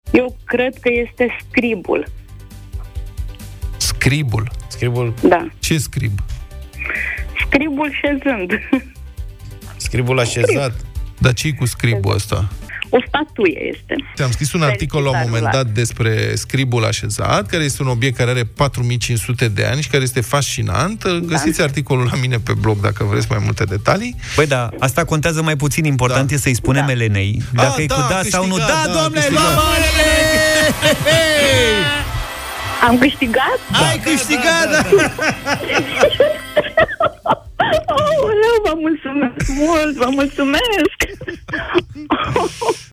Soluția câștigătoare de la concursul ”Ghicești și Călătorești” a venit miercuri, în direct la Europa FM!